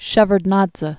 (shĕvərd-nädzə), Eduard Amvrosiyevich 1928-2014.